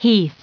Prononciation du mot heath en anglais (fichier audio)
Prononciation du mot : heath